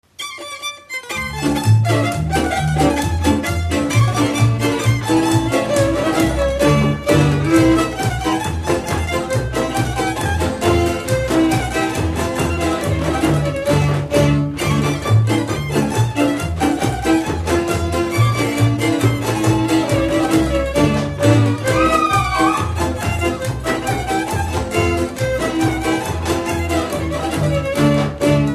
Dallampélda: Hangszeres felvétel
Alföld - Pest-Pilis-Solt-Kiskun vm. - Bogyiszló
brácsa
tamburabrácsa
bőgő
Műfaj: Ugrós
Stílus: 7. Régies kisambitusú dallamok